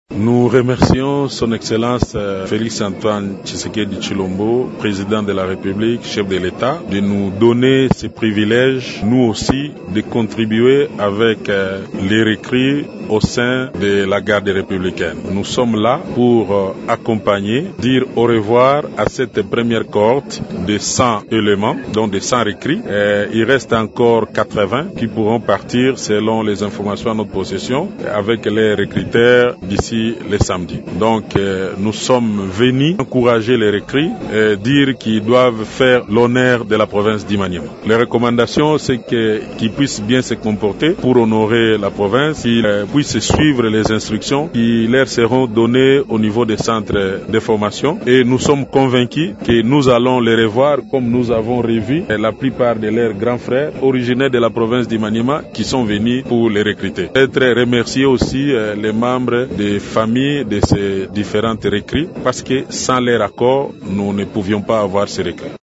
On écoute le gouverneur du Maniema, Mussa Kabwankubi, dans cet extrait :